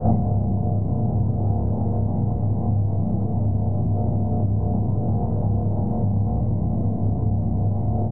Engine_high.ogg